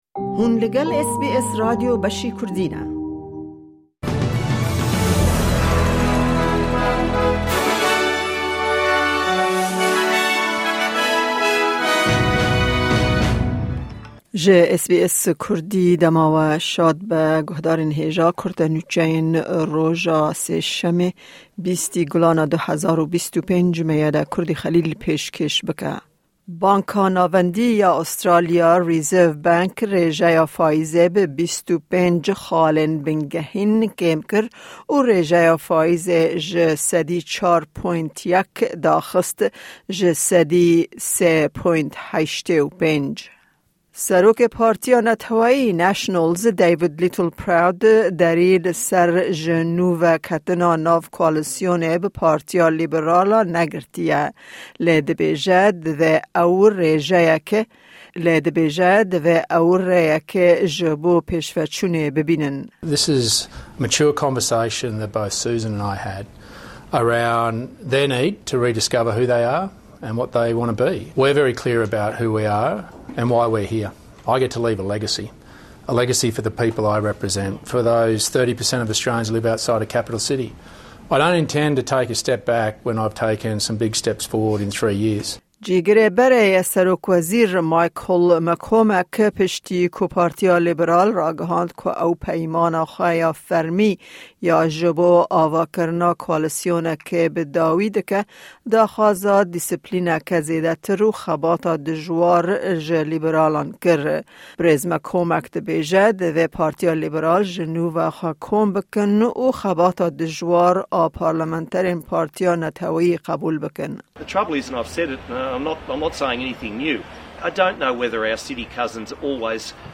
Kurte Nûçeyên roja Sêşemê 20î Gulana 2025